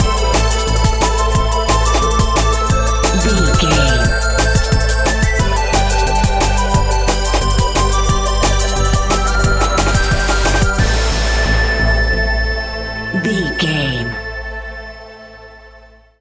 Aeolian/Minor
Fast
aggressive
dark
driving
energetic
groovy
drum machine
synthesiser
sub bass
synth leads